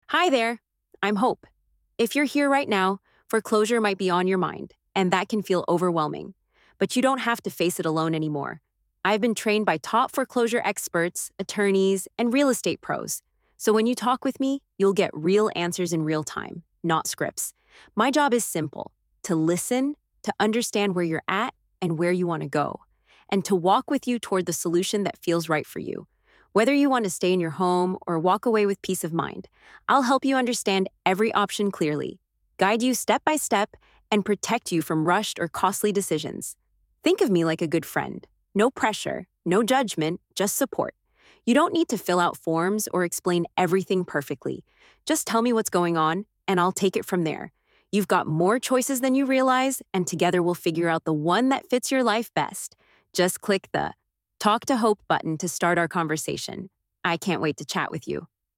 Hope - Your AI Assistant